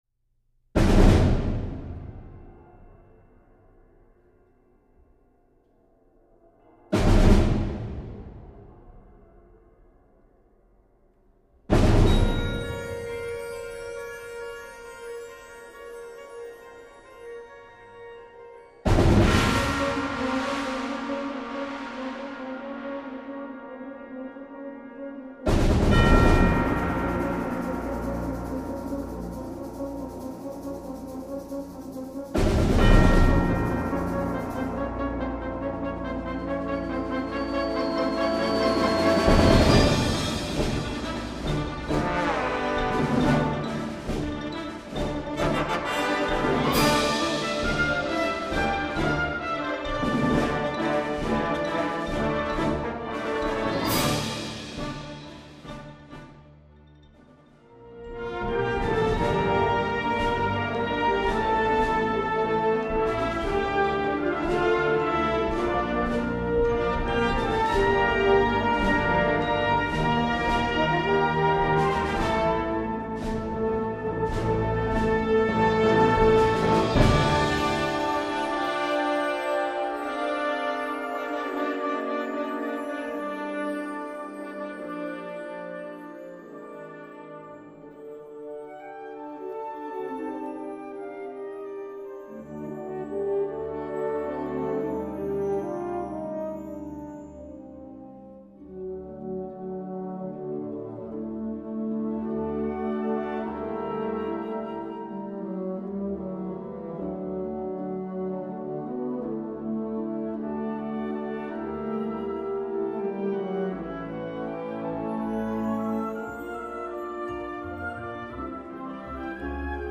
Choeur et Piano Pour Soprani et Contralti avec Sol